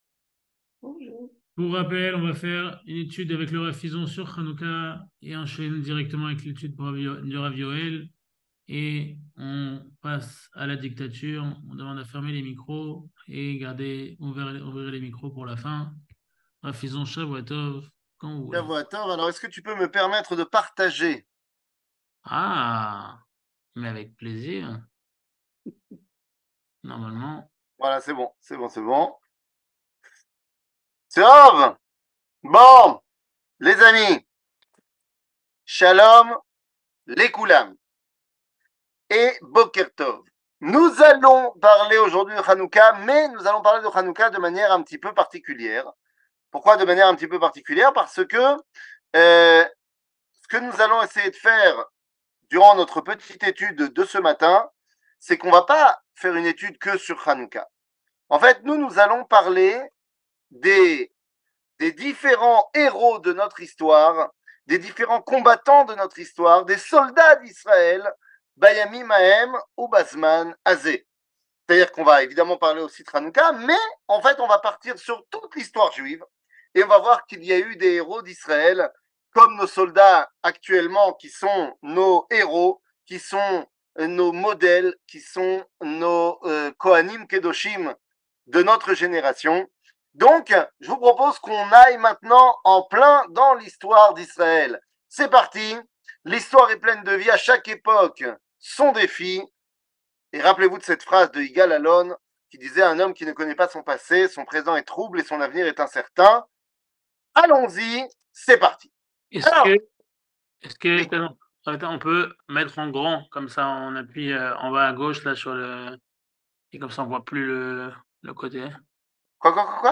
שיעורים, הרצאות, וידאו
שיעור